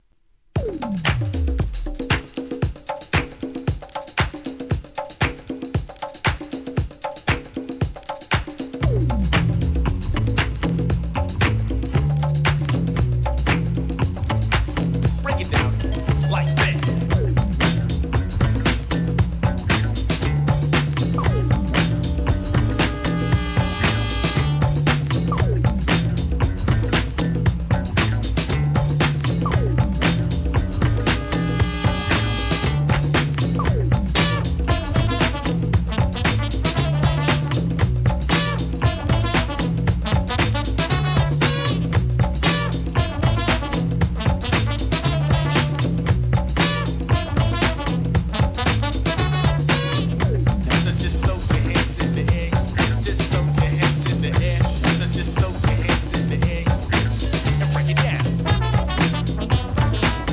HIP HOP/R&B
生のベース、ギター、ドラム、サッ クスがセッションするBREAK BEATS